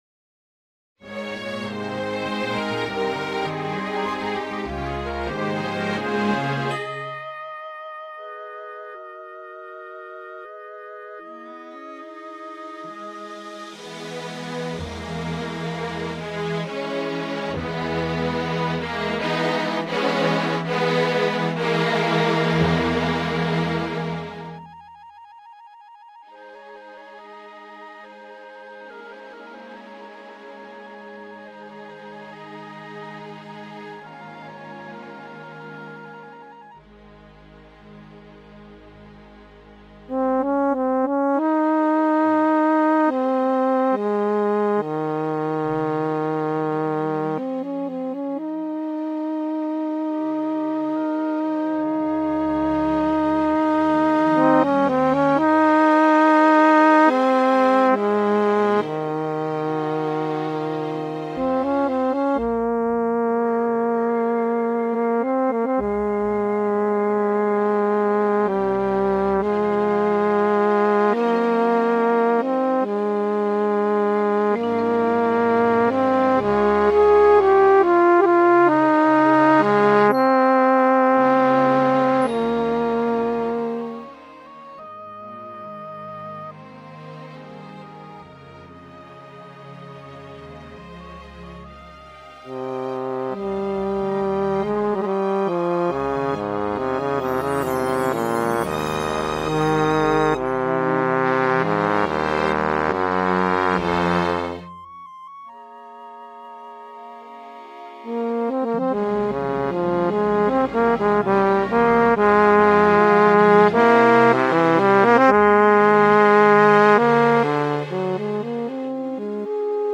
Single movement.
– Solo bass trombone.
– Timpani